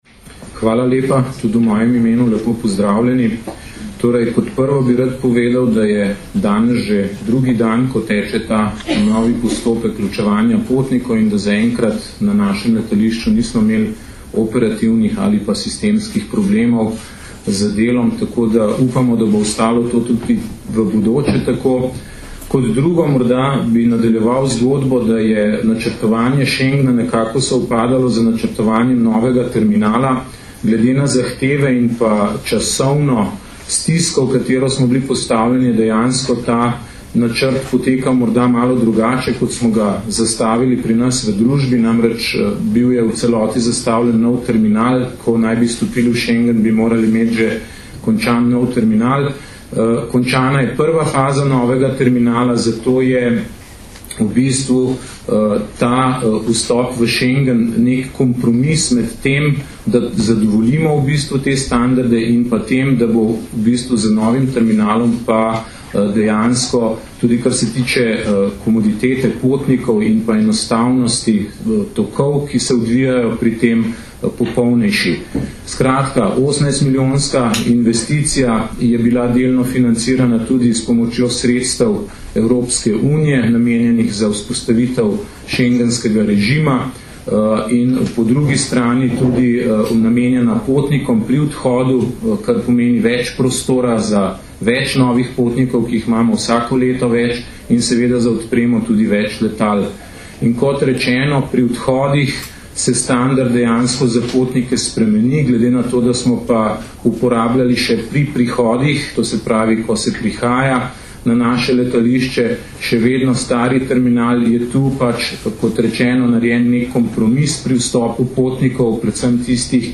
Predstavniki Ministrstva za notranje zadeve, Policije in Aerodroma Ljubljana so na današnji novinarski konferenci na letališču Jožeta Pučnika Ljubljana predstavili zgodovinski pomen dogodka za Slovenijo in druge schengenske države, še zlasti pri zagotavljanju varnosti v schengenskem prostoru.